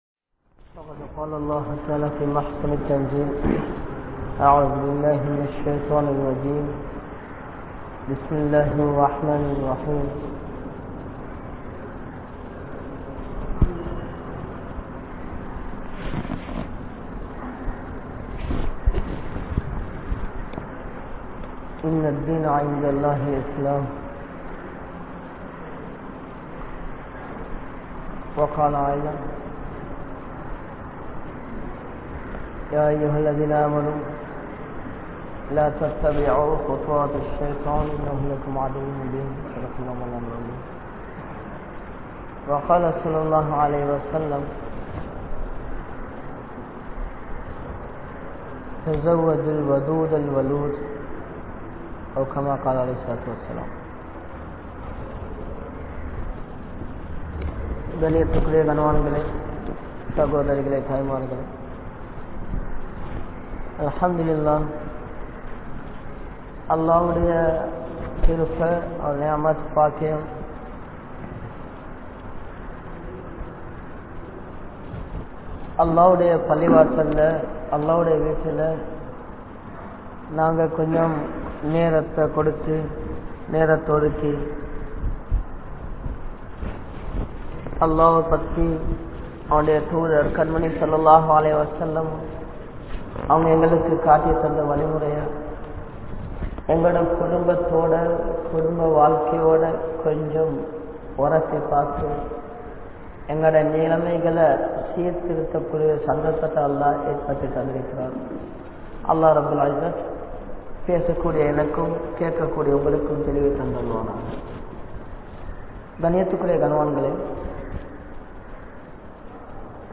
Depopulation | Audio Bayans | All Ceylon Muslim Youth Community | Addalaichenai